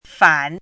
chinese-voice - 汉字语音库
fan3.mp3